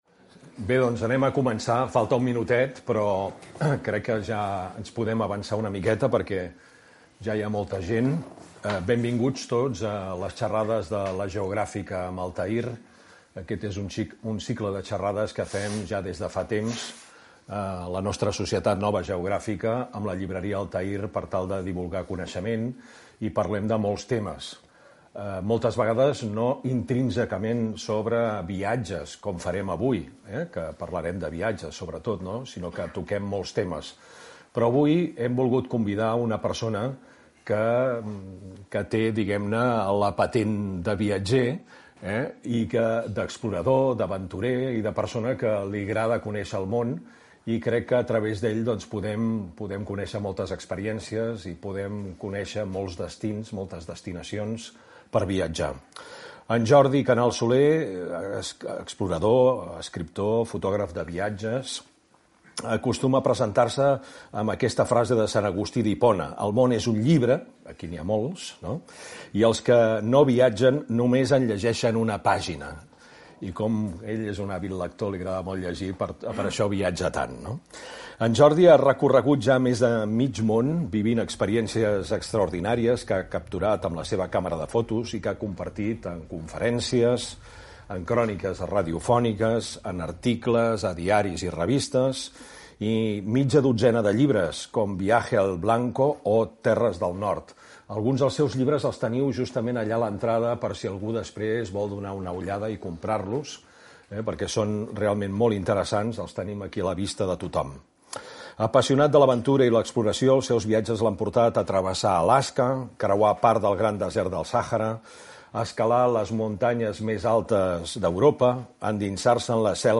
xerrada